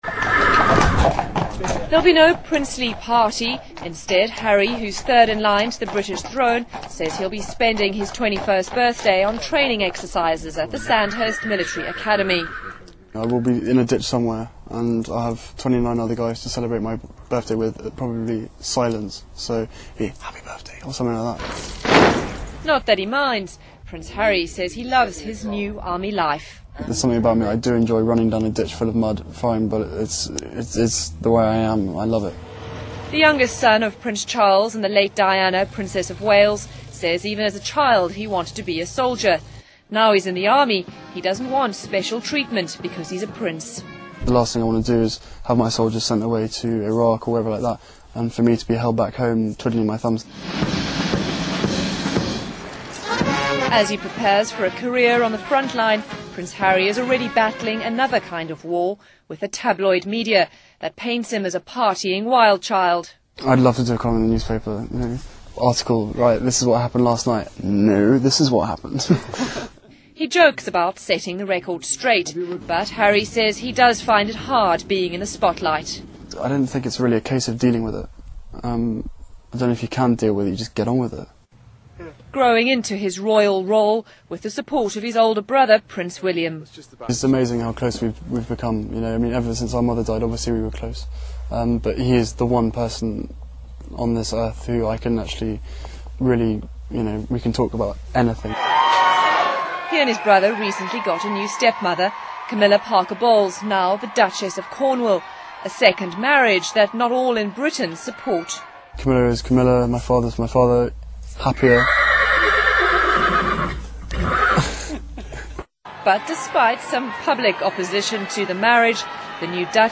英国哈里王子21周岁专访 听力文件下载—在线英语听力室